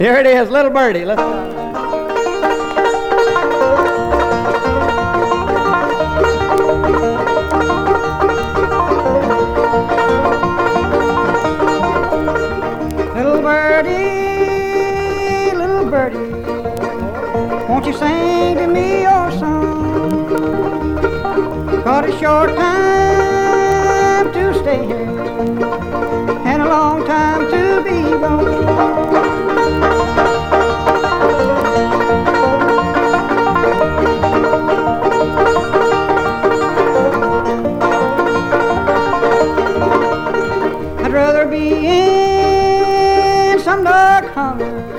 Folk, Country, Appalachian Music, Bluegrass　USA　12inchレコード　33rpm　Mono